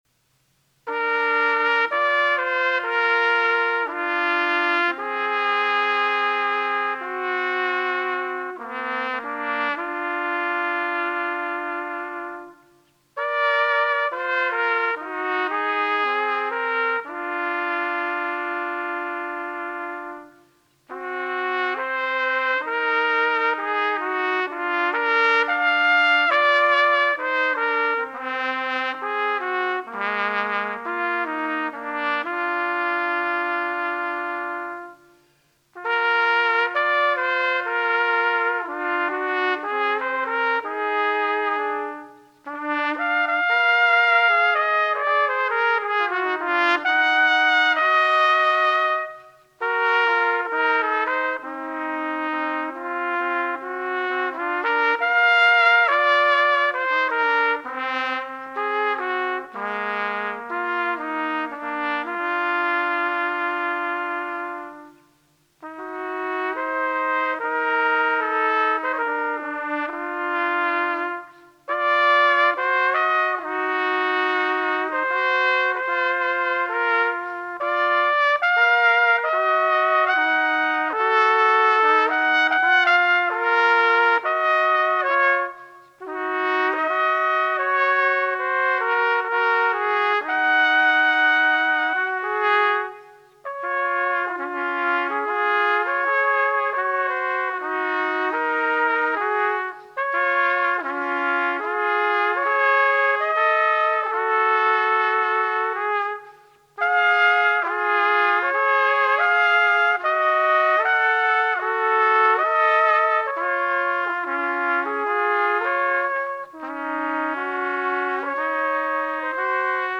Solo Trumpet
Willwerth Essays for Unaccompanied Trumpet